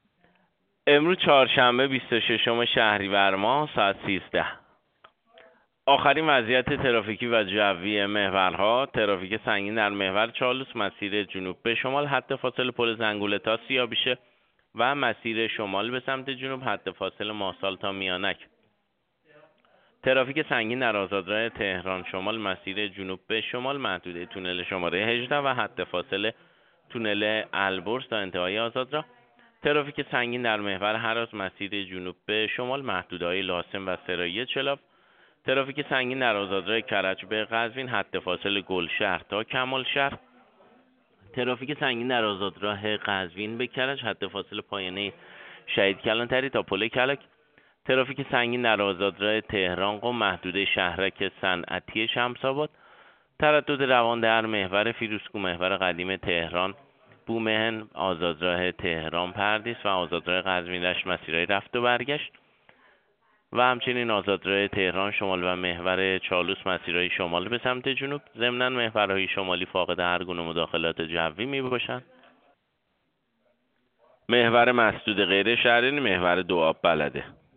گزارش رادیو اینترنتی از آخرین وضعیت ترافیکی جاده‌ها ساعت ۱۳ بیست و ششم شهریور؛